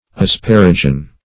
Asparagine \As*par"a*gine\, n. [Cf. F. asparagine.] (Chem.)